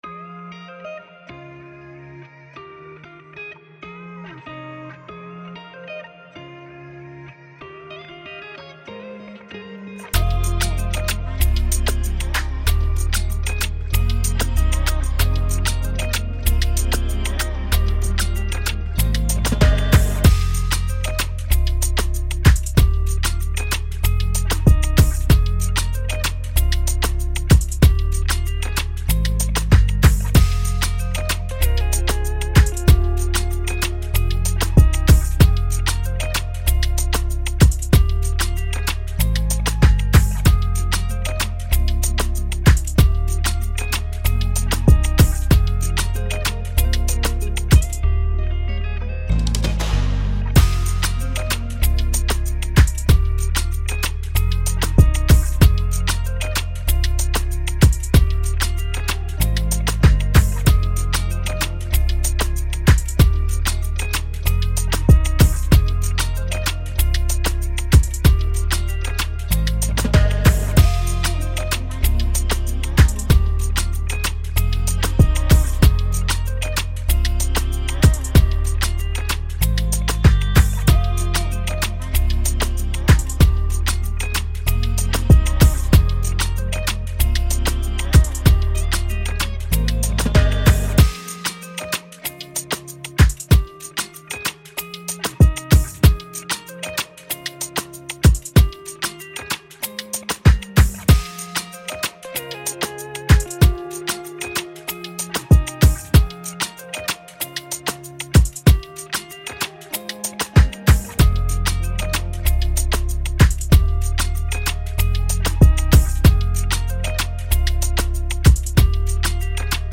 Download Afrobeat
Afrobeat instrumental